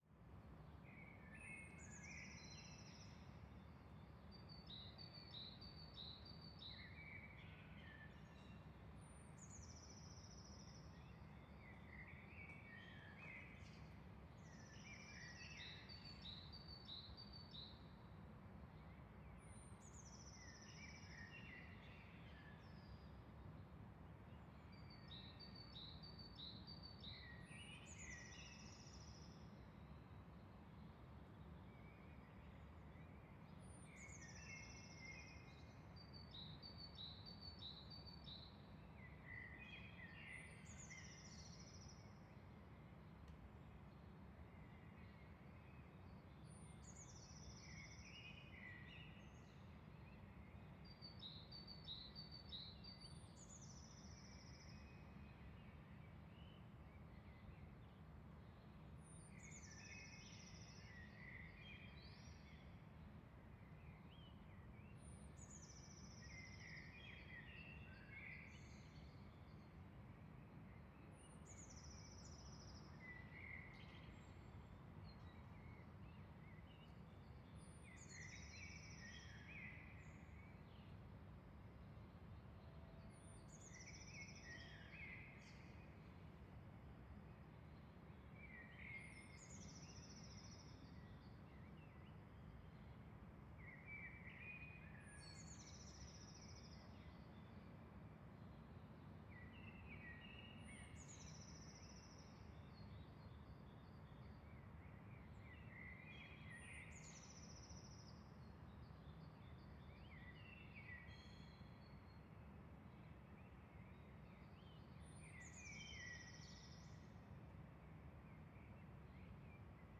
62 minute drifting sand ambience national park Loonse en Drunense Duinen - Netherlands 0953 AM 250404_1067
ambiance ambience ambient atmospheric background-sound birds calm drifting-sand-dunes